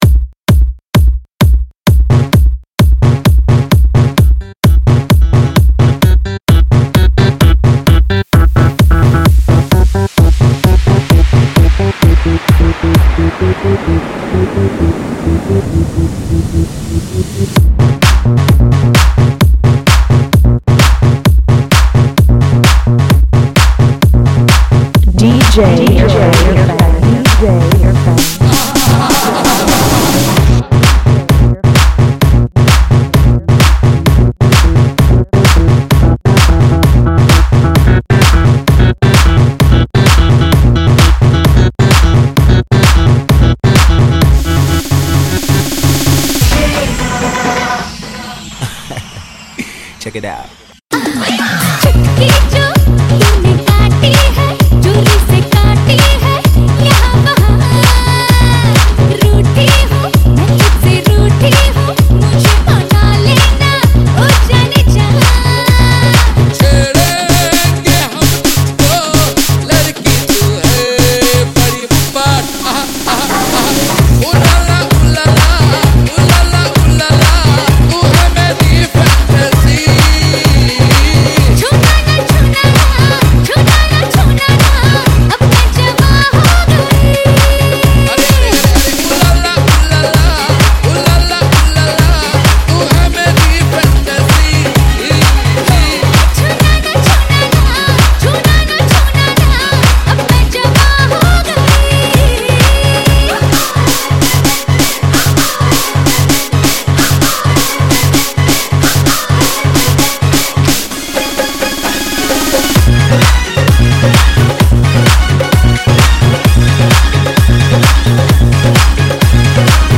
Single Dj Mixes